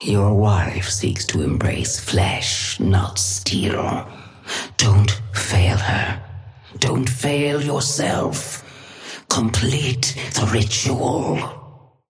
Sapphire Flame voice line - Your wife seeks to embrace flesh, not steel.
Patron_female_ally_dynamo_start_03.mp3